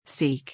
語呂合わせ： しく しくしてる人を 探す 目次 seekの意味 seekの発音 seekの過去形 seekとsearch、look forの違い seekの意味 [他動]探し求める seekの発音 síːk、シーク seekの過去形 seekの seekの過去形・過去分詞形 は「 sought/ sɔt/ソト 」です。